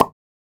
satisfying-pop.wav